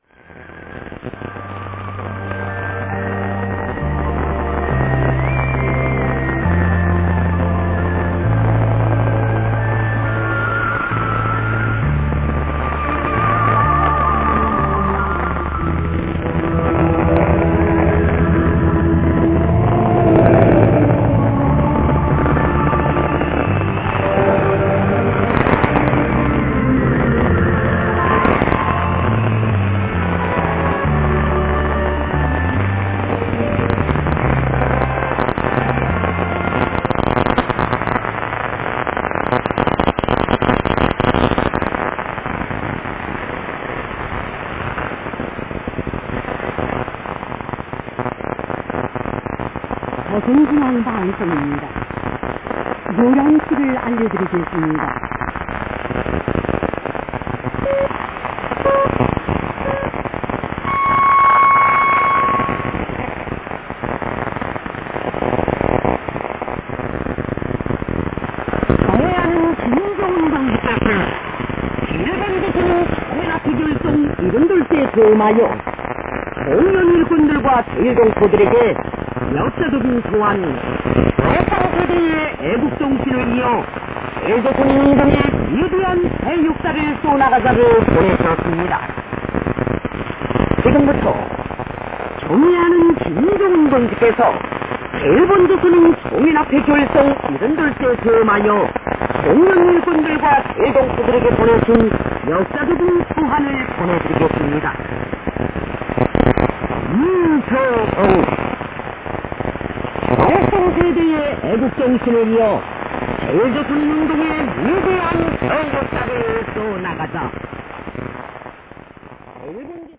11680kHz KCBSを聞いてみましたが、変調にブツブツという音が混じっており耳障りですOrz
<受信地：埼玉県戸田市 荒川河川敷 RX:ICF-SW7600GR ANT:Built-in whip>
※00:49-00:55 女性アナ時報アナウンス「朝鮮中央放送イムニダ。ヨラナシ(11時)ルル アルリョドゥリムニダ」